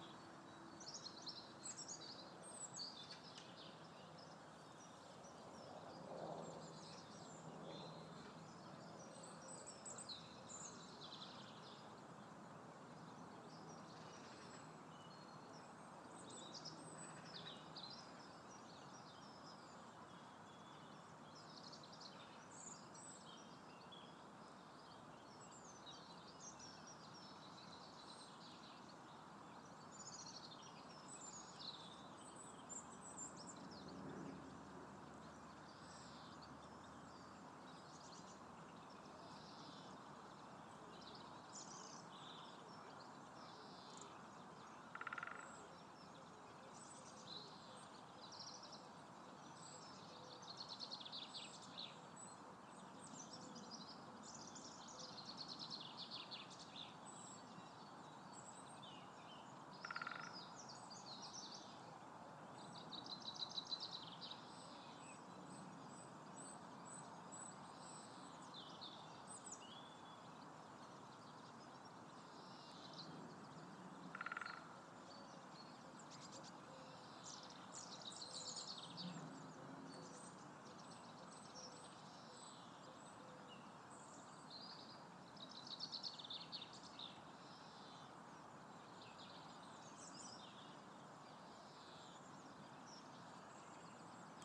The birds are very noisy in Clemson Park today.